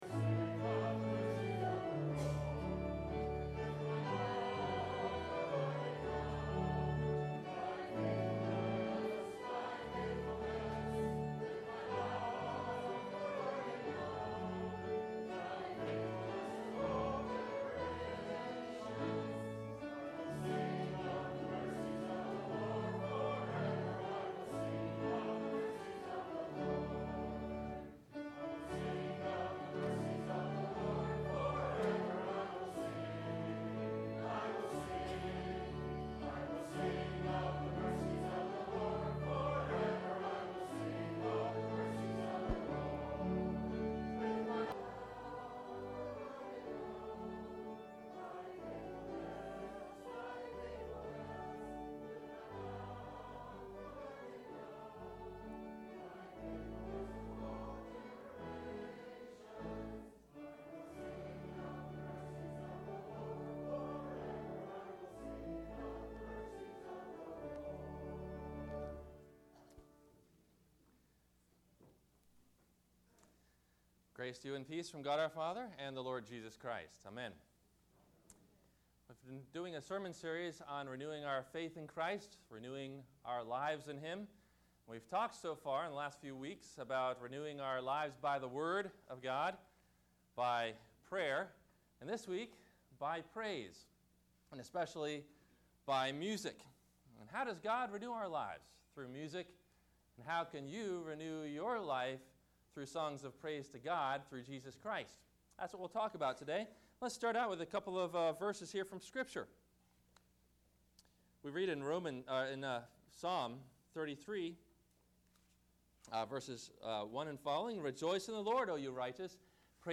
The Story in Joy – Christmas Day – Sermon – December 25 2010